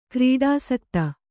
♪ krīḍāsakta